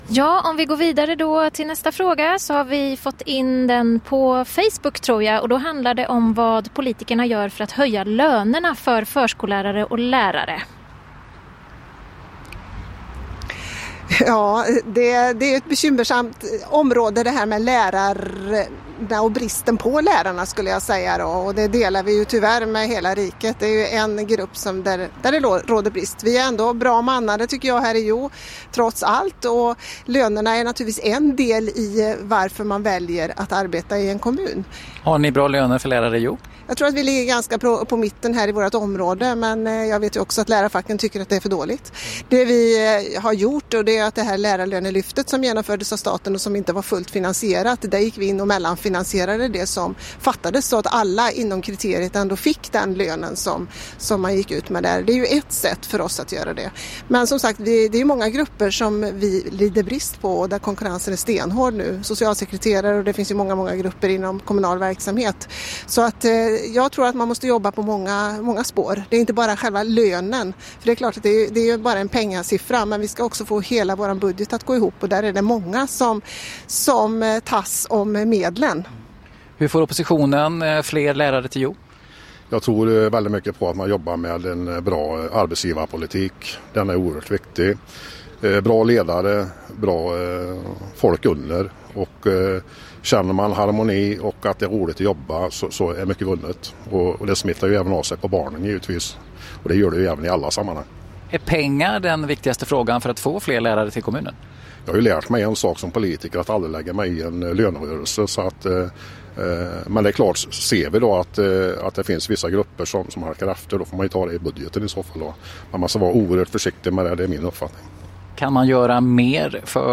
Där eftermiddagsprogrammet sändes från Kulturkvarteret.
Catrin Hulmarker (M) och oppositionsrådet Pierre Rydén (S) svarade på lyssnarnas frågor om Hjo.